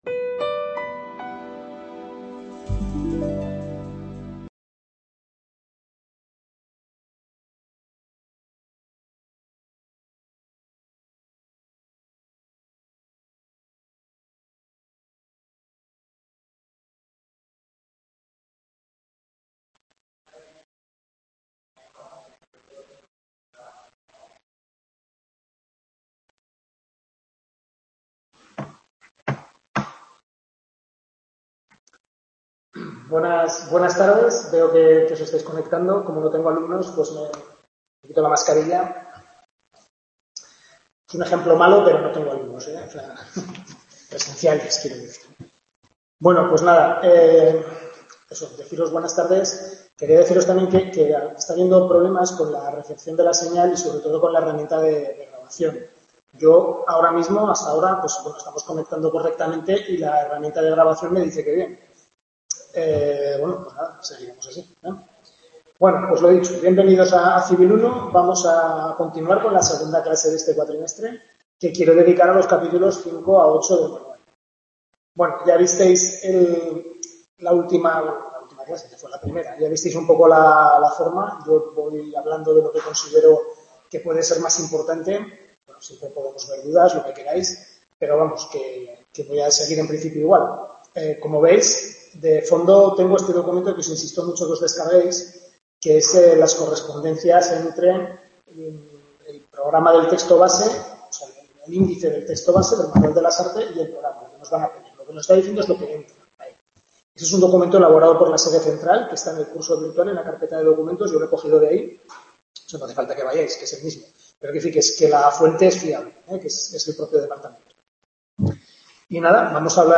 Tutoría Civil I, capítulos 5 a 8 del Manual